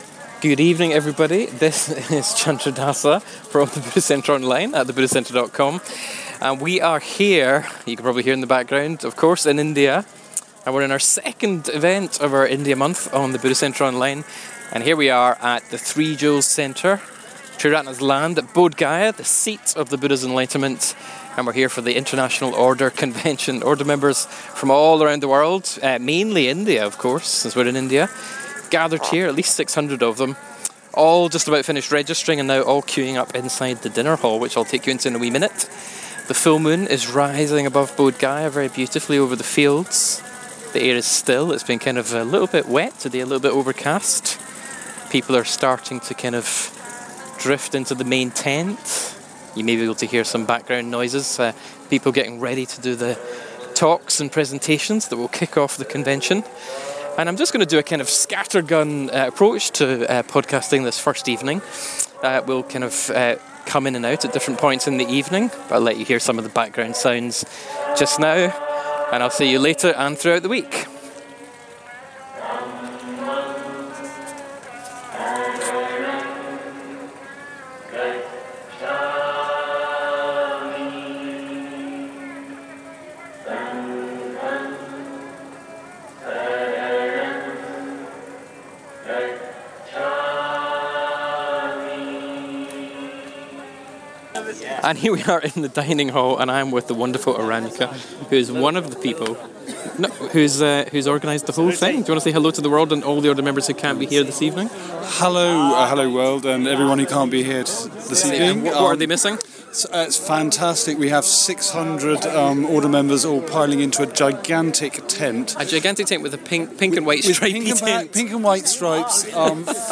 From all over the world members of the Triratna Buddhist Order have come to Bodh Gaya in Bihar, India to celebrate their community and sense of shared aspirations and values.
Here's a first smorgasbord of sound - from under the pink and white awning, the voices of Order members greeting each other and their fellows listening in around the world...